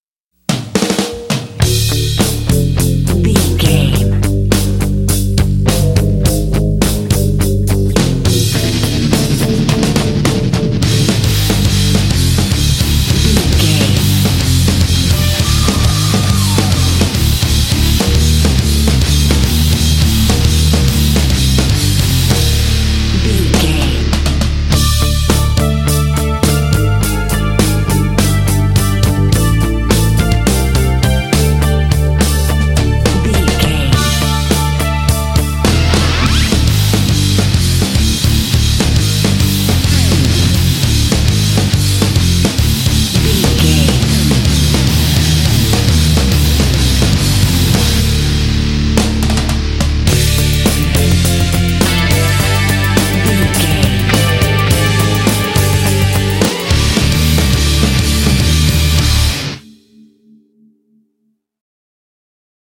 Epic / Action
Aeolian/Minor
powerful
energetic
electric guitar
bass guitar
drums
synthesiser
heavy metal
classic rock